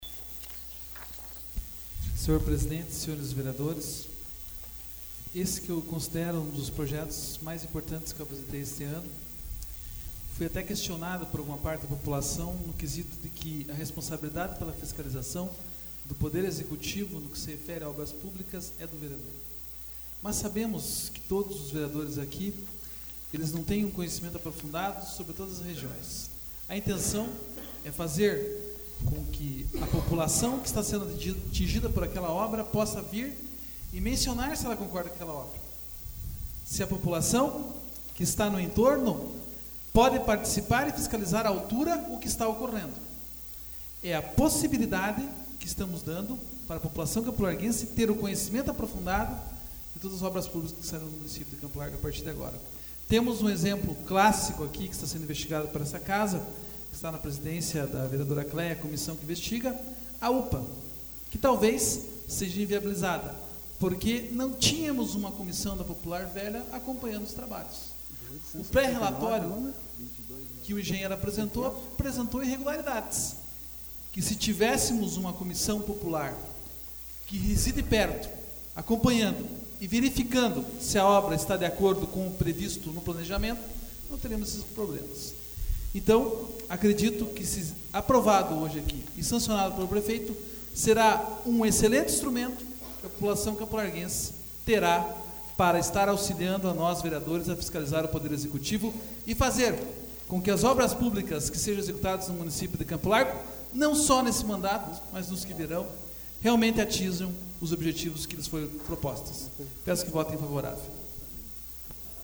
Discussão AVULSO 17/12/2013 João Marcos Cuba